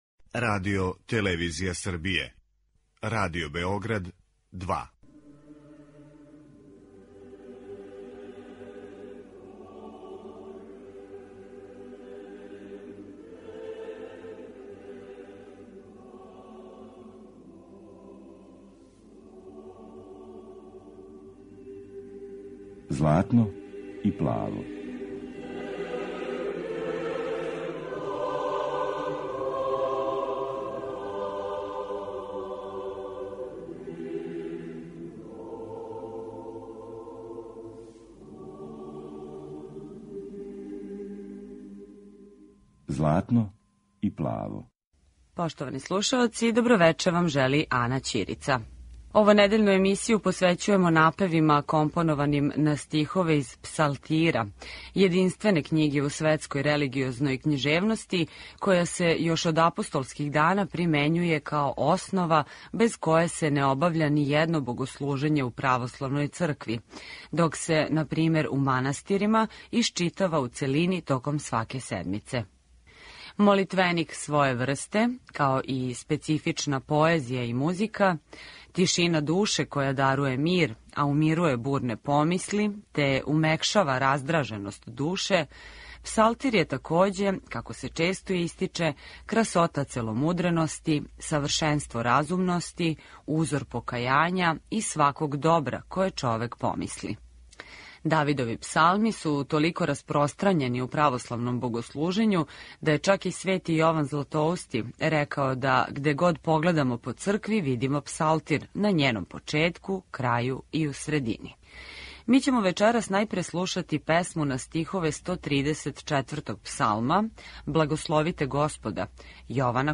Појање монаха из манастира Симонопетра на Светој Гори.
Емисија посвећена православној духовној музици.